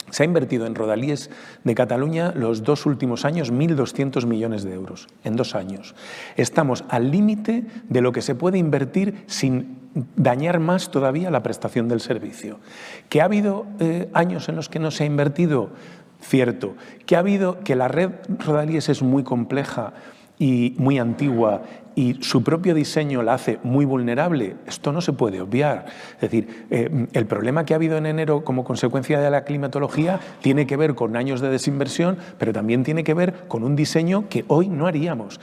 Ho ha dit en un col·loqui amb periodistes a l’Ateneu de Madrid.